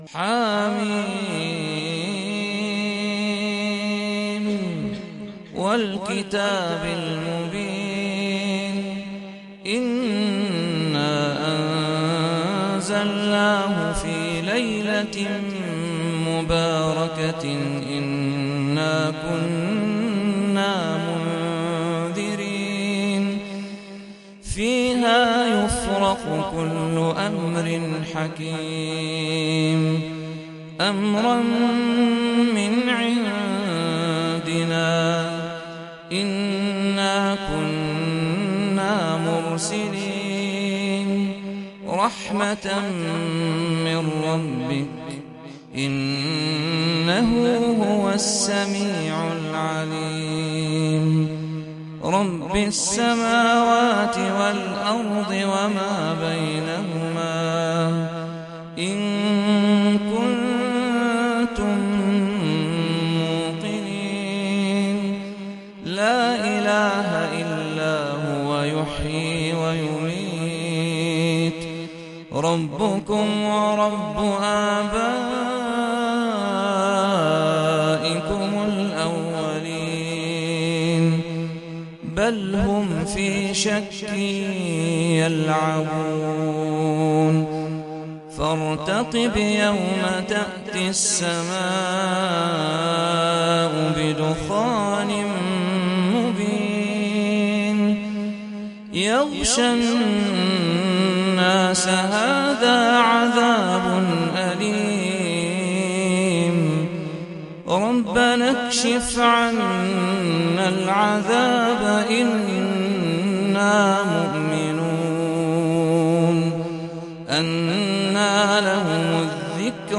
سورة الدخان - صلاة التراويح 1446 هـ (برواية حفص عن عاصم)